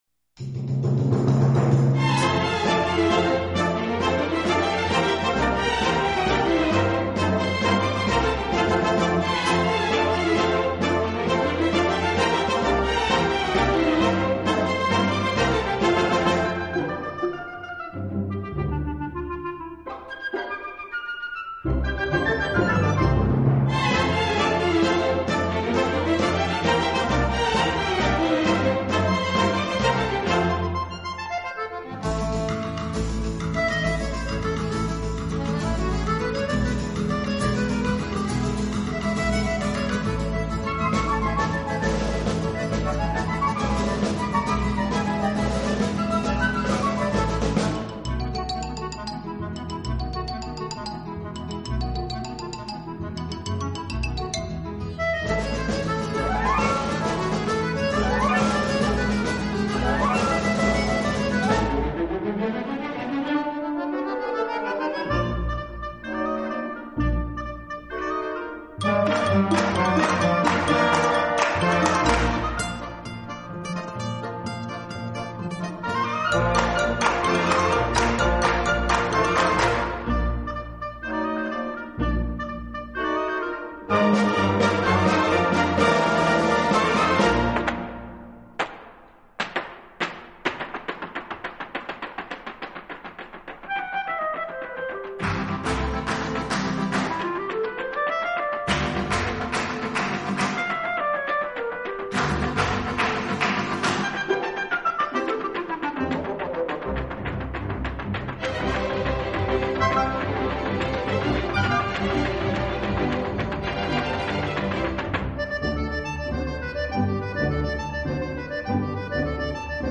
轻音乐
顶级轻音乐
这个乐团的演奏风格流畅舒展，
旋律优美、动听，音响华丽丰满。